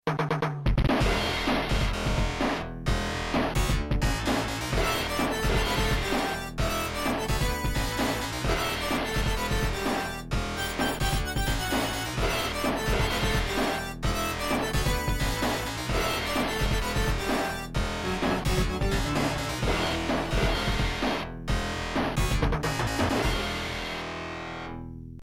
All of this music is from the arcade version of the game.